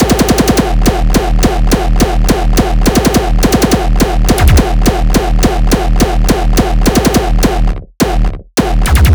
UT_22_Bassdrumloop_210.wav